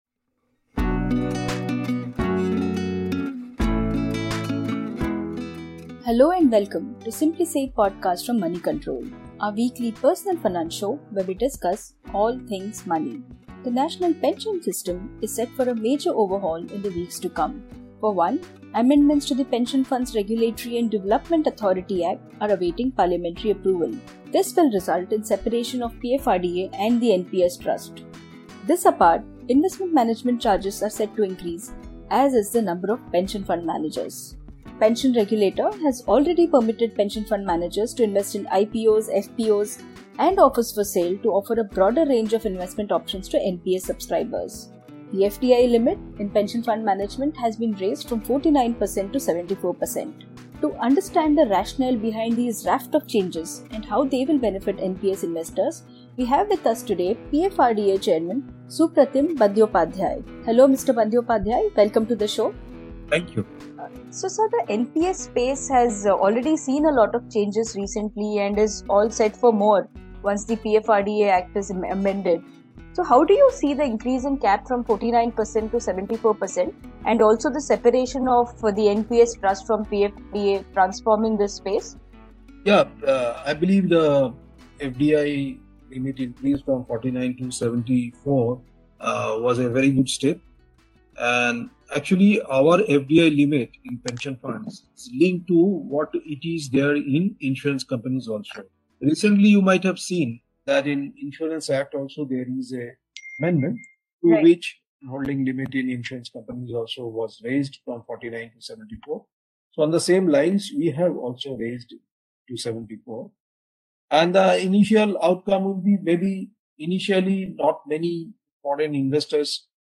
To understand the rationale behind these major changes and what they mean for NPS subscribers, we are joined by PFRDA chairman Supratim Bandyopadhyay. Tune in to Simply Save for this exclusive interview.